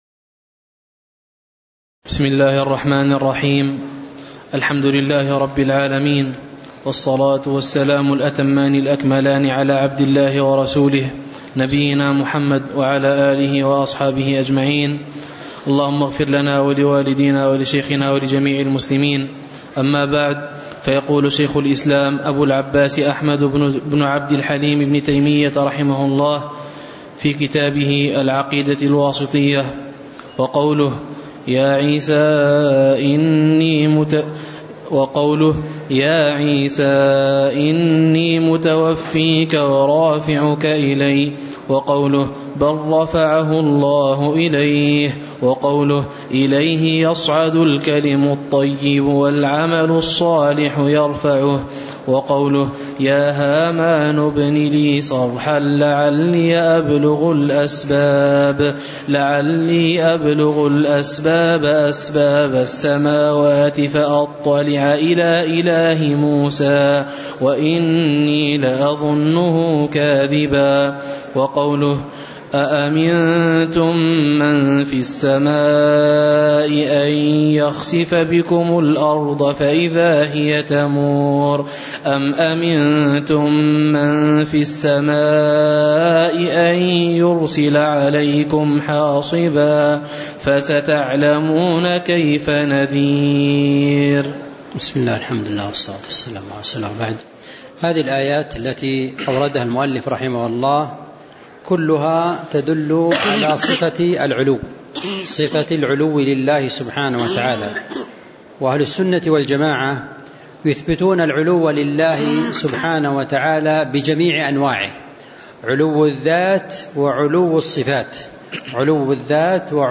تاريخ النشر ٥ ذو القعدة ١٤٤٠ هـ المكان: المسجد النبوي الشيخ